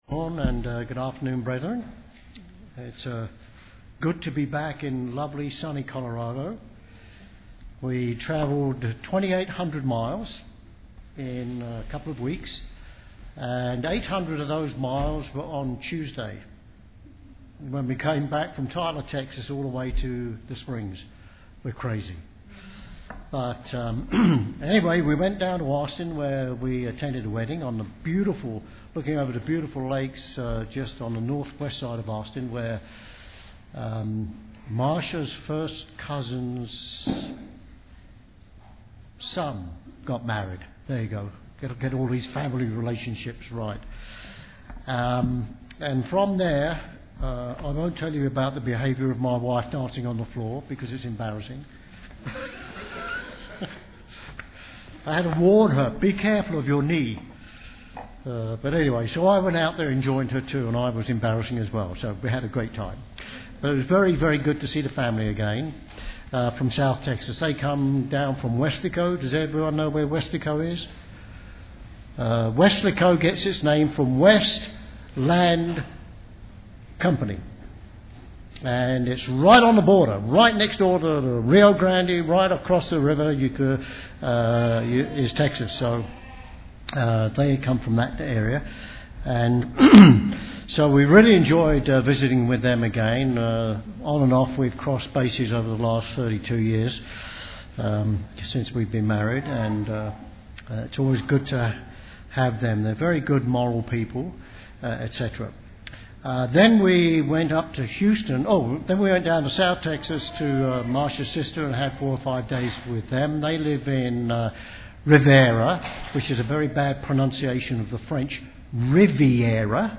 This sermon addresses the basic cause of division among brethren.
Given in Colorado Springs, CO